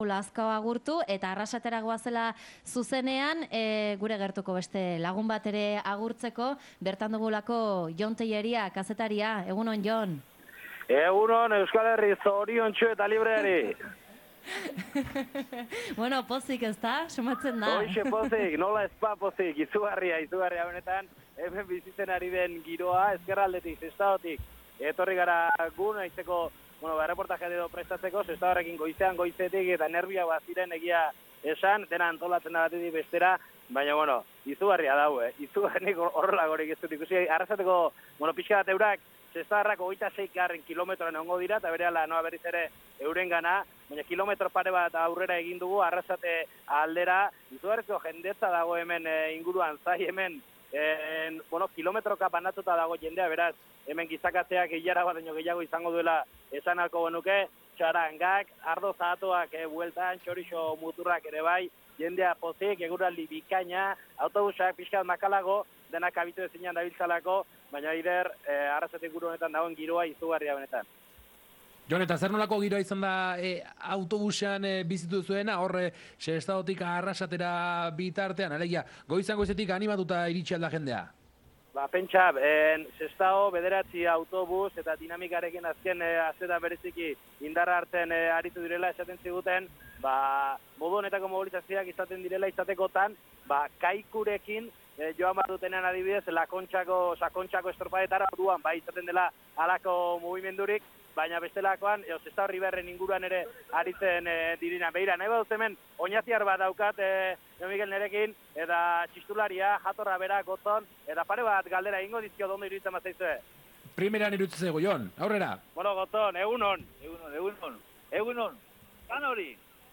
Arrasatetik zuzenean.